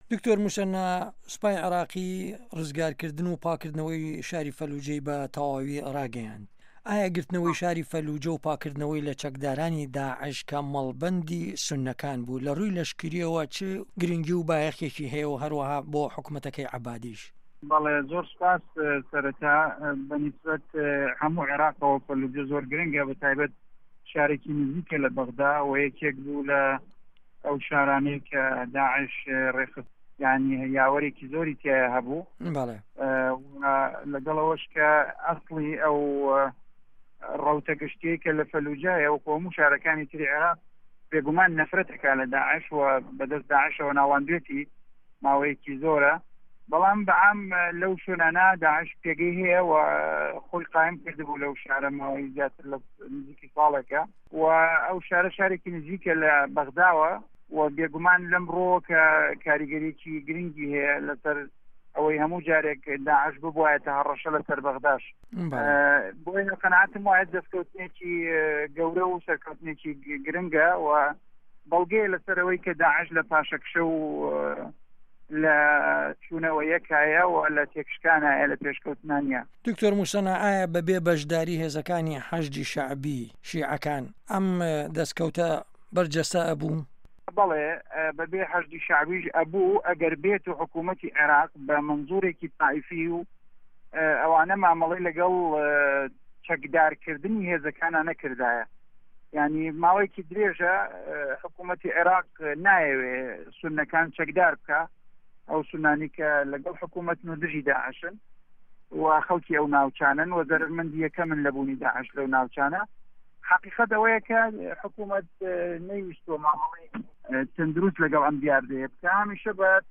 وتوێژ لەگەڵ دکتۆر موسەنا ئەمین